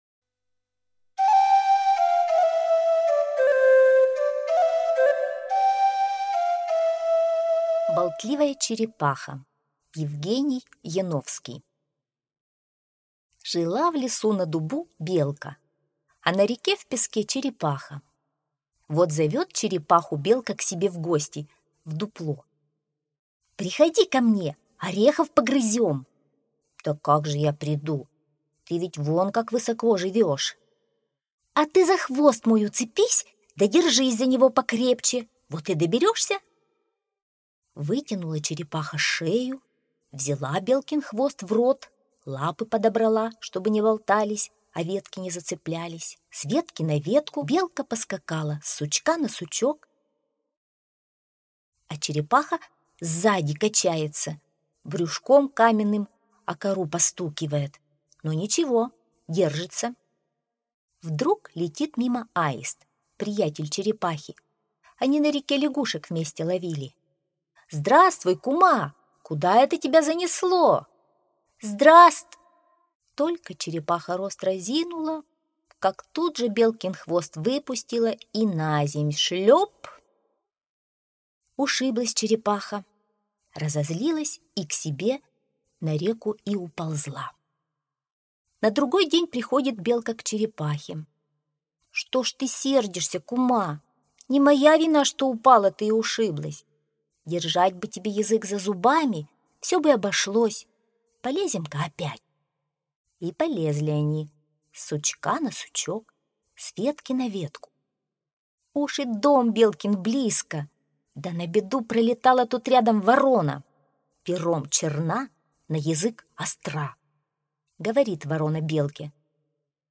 Болтливая черепаха - аудиосказка Яновского - слушать онлайн